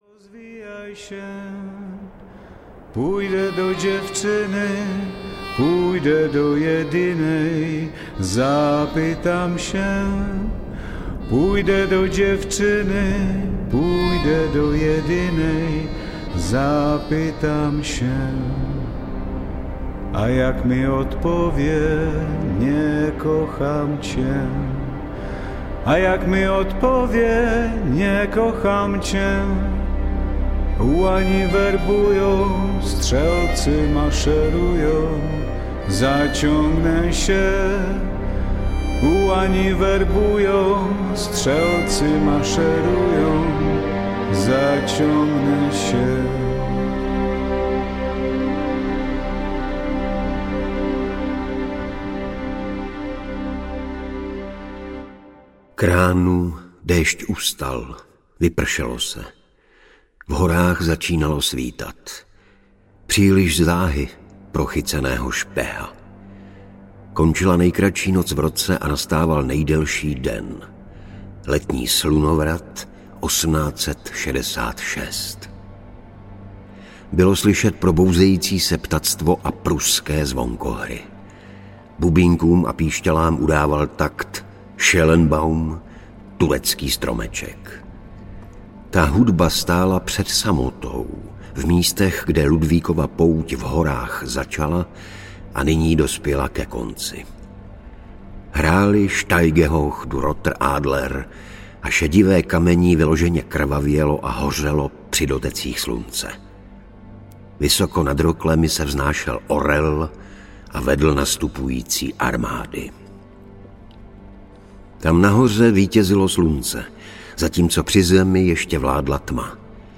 Život za podpis audiokniha
Ukázka z knihy
• InterpretIgor Bareš